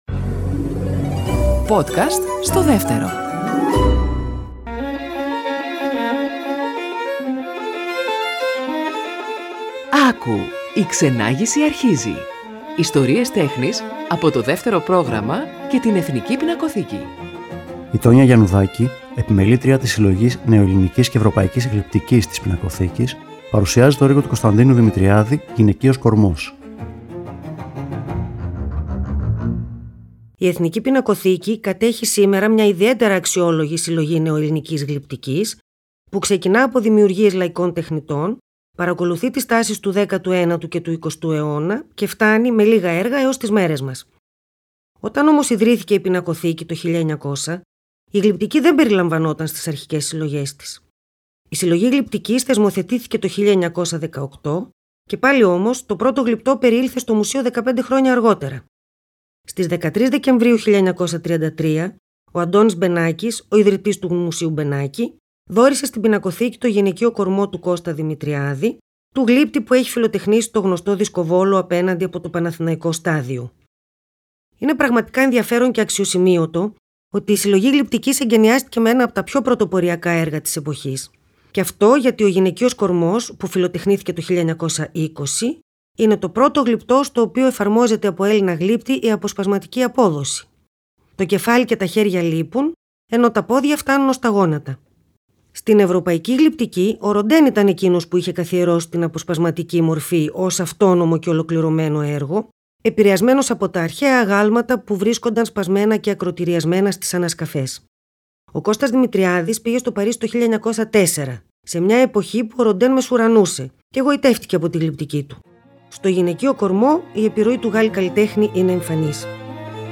Κείμενο / αφήγηση